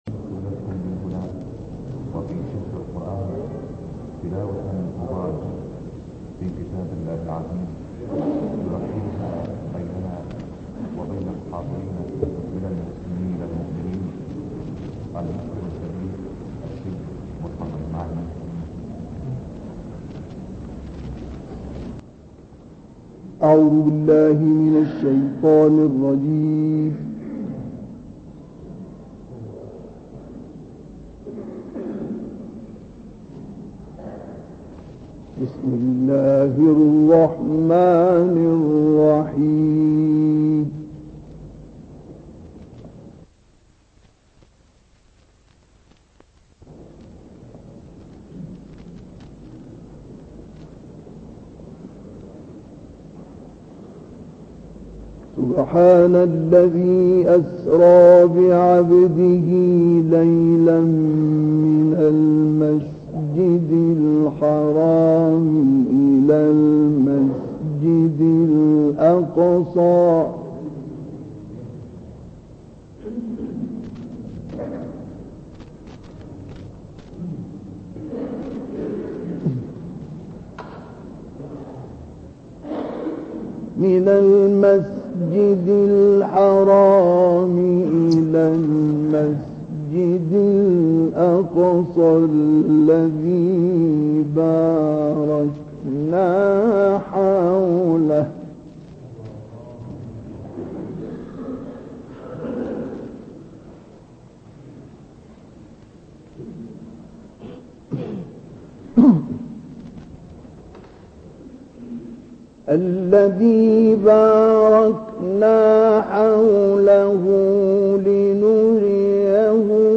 تلاوت «مصطفی اسماعیل» در مسجد عبدالقادر گیلانی در بغداد
گروه شبکه اجتماعی: تلاوت آیاتی از سوره اسراء و طارق با صوت مصطفی اسماعیل که در مسجد جامع عبدالقادر گیلانی در شهر بغداد اجرا شده است، ارائه می‌شود.